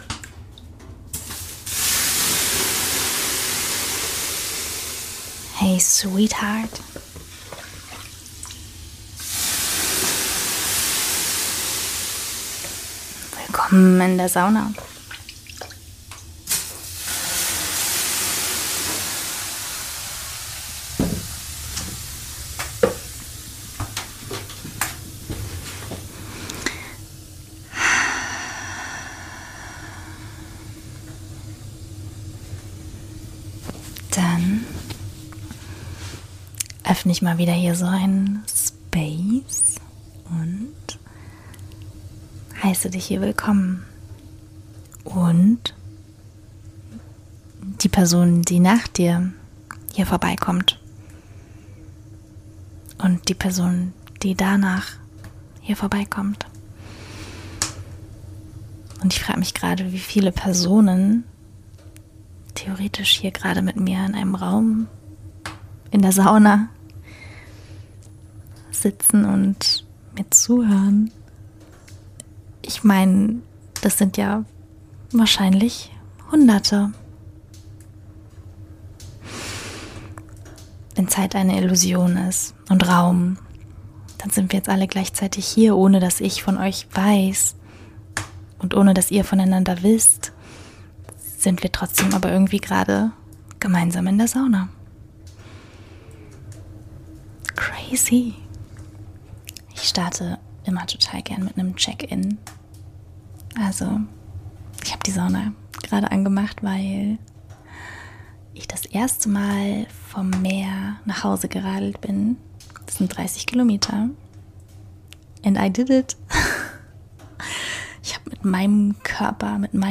In dieser Folge nehme ich dich mit in die Sauna. Zu einem weiteren Podcast ohne Skript.